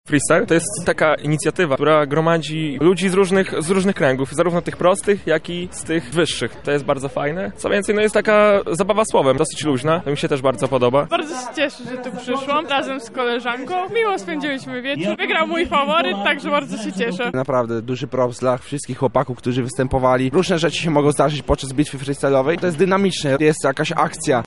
Młodzi raperzy rywalizowali podczas Medykaliów o zwycięstwo na Bitwie Freestyle’owej
Bitwa-Freestyle.mp3